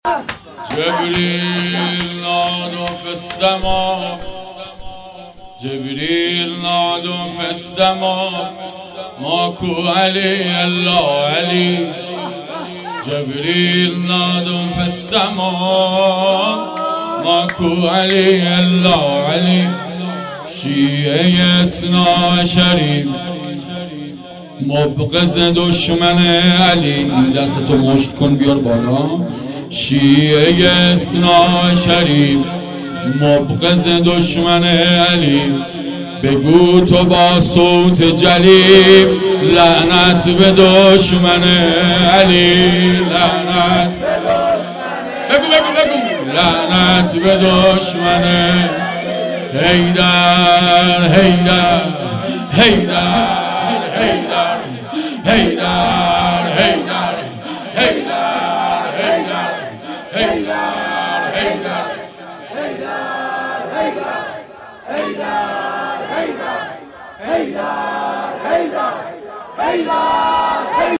شهادت امام جواد علیه السلام